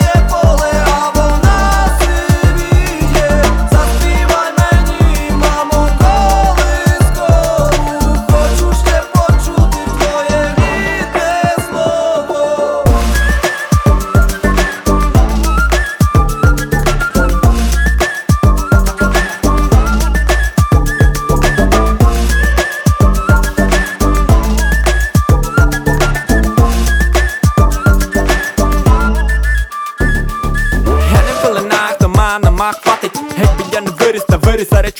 Жанр: Поп / Украинский рок / Украинские